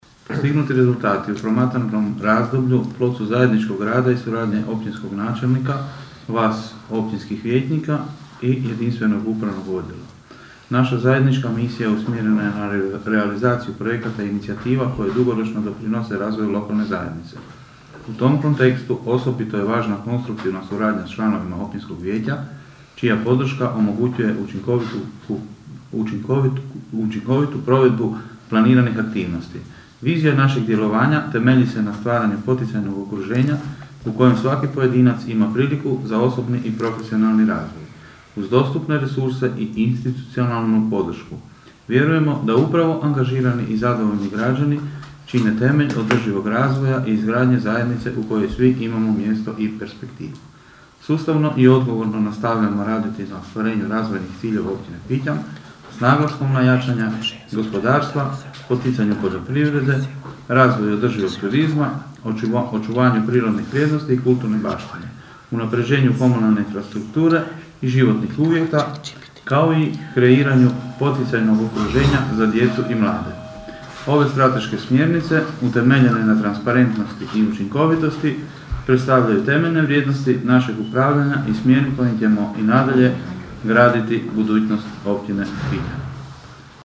Na sjednici Općinskog vijeća Pićna, načelnik Dean Močinić podnio je izvješće o radu za proteklih šest mjeseci, sukladno zakonskoj obvezi.
ton – Dean Močinić), istaknuo je Močinić.